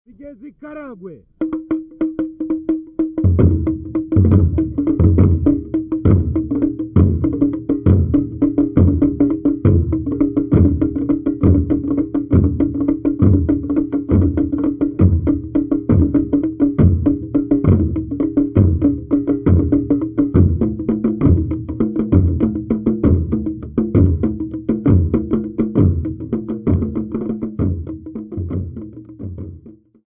8 Hutu men
Folk music--Africa
Field recordings
Indigenous folk drum rhythms, with 2 conical drums, 5 laced and closed cylindrical drums with wooden beaters, and 1 laced and cylindrical treble drum with wooden beaters.